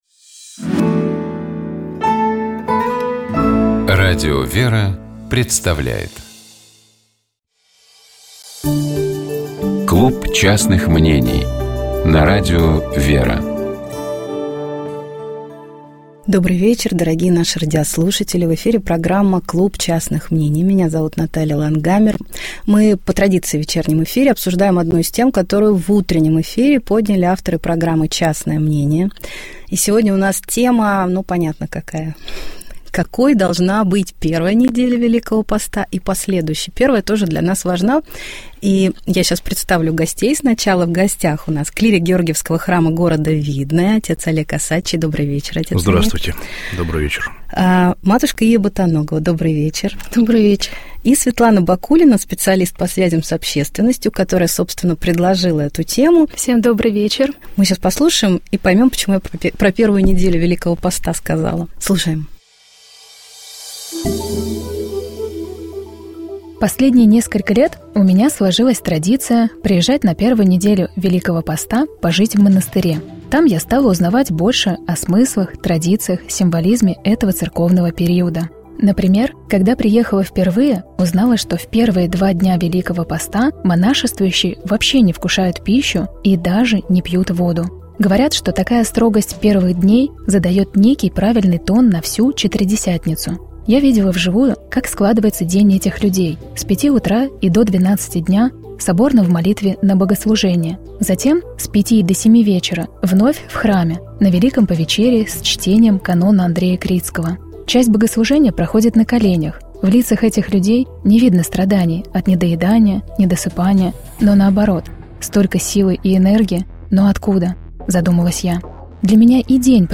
В этот раз участниками дискуссии в программе «Клуб частных мнений» были